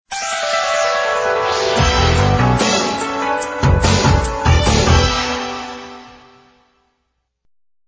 The recycled jingle collection: